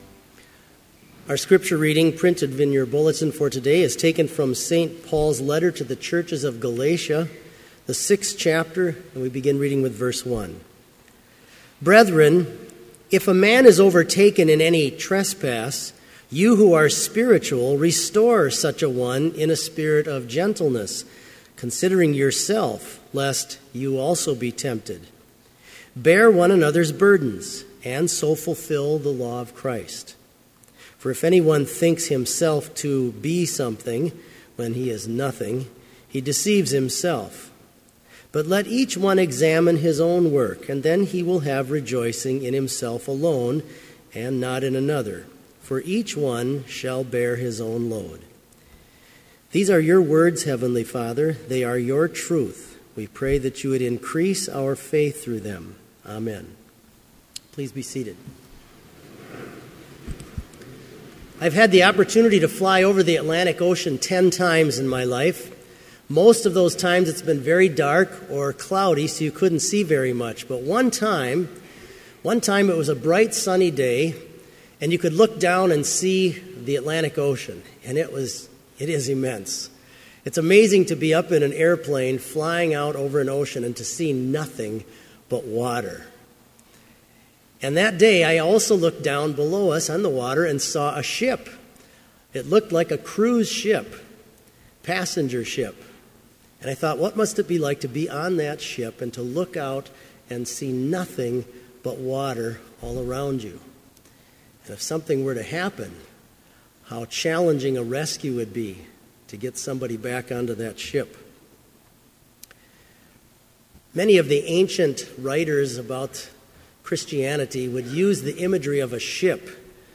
Sermon audio for Chapel - February 6, 2015